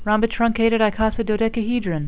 (rom-bi-trun-ca-ted   i-co-si-do-dec-a-he-dron)